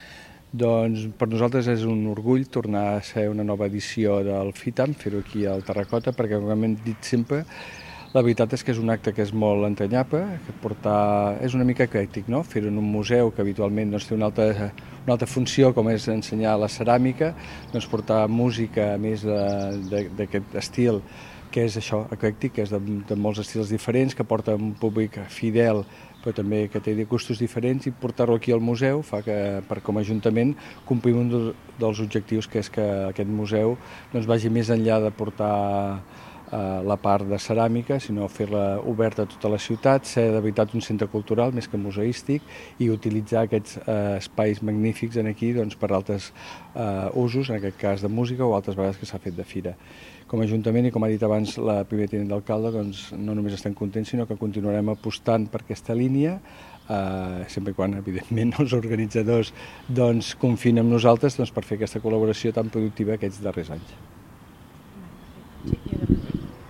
Durant la presentació del cartell, celebrada al mateix museu, l’alcalde de la Bisbal, Òscar Aparicio, va destacar la singularitat del festival dins l’oferta cultural de l’estiu empordanès.